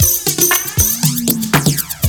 DRUMFILL08-L.wav